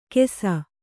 ♪ kesa